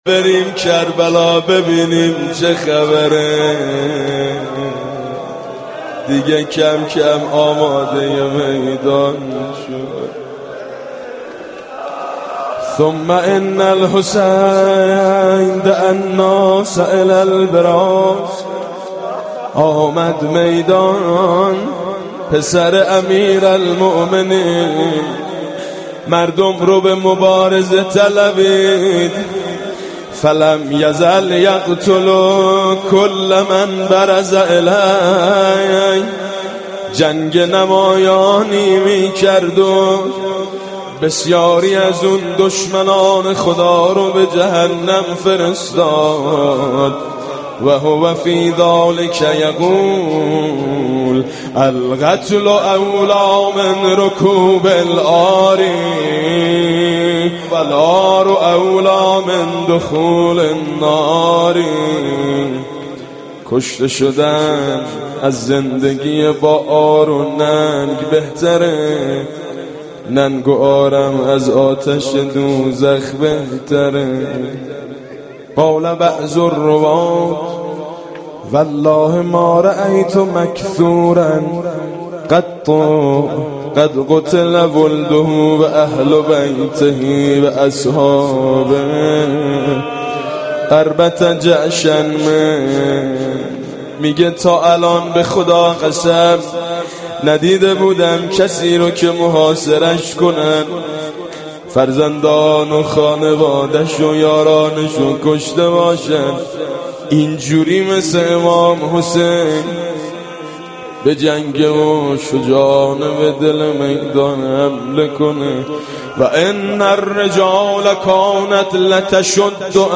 نعي الامام الحسين (ع) بصوت ميثم مطيعي (جديد 3)
اناشيد